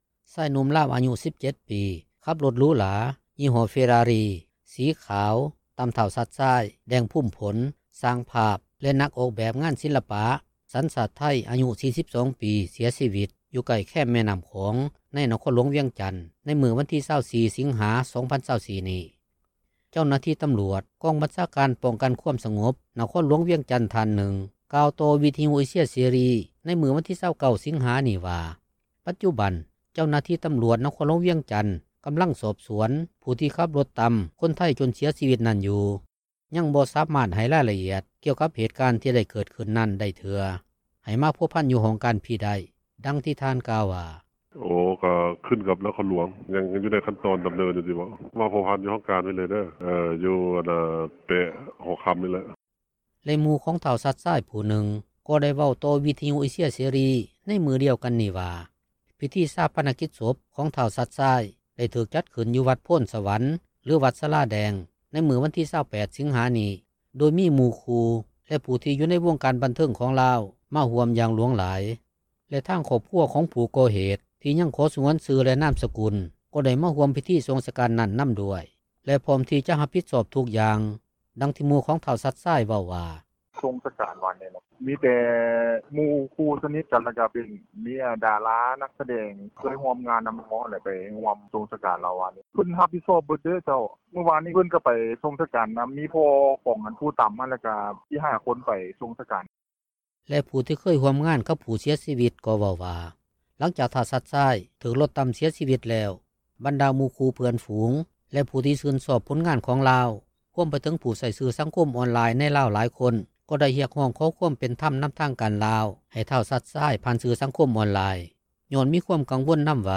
ເຈົ້າໜ້າທີ່ຕໍາຫຼວດ ກອງບັນຊາການ ປ້ອງກັນຄວາມສະຫງົບ ນະຄອນຫຼວງວຽງຈັນ ທ່ານນຶ່ງ ກ່າວຕໍ່ວິທຍຸເອເຊັຍເສຣີ ໃນມື້ວັນທີ 29 ສິງຫານີ້ວ່າ ປັດຈຸ ບັນ ເຈົ້າໜ້າທີ່ຕໍາຫຼວດນະຄອນຫຼວງວຽງຈັນ ກໍາລັງສອບສວນ ຜູ້ທີ່ຂີ່ລົດຕໍາຄົນໄທຈົນເສຍຊີວິດນັ້ນຢູ່, ຍັງບໍ່ສາມາດໃຫ້ລາຍລະອຽດ ກ່ຽວກັບເຫດການທີ່ໄດ້ເກີດຂື້ນນັ້ນໄດ້ເທື່ອ ໃຫ້ມາພົວພັນຢູ່ຫ້ອງການພີ້ໄດ້ ດັ່ງທີ່ທ່ານກ່າວວ່າ: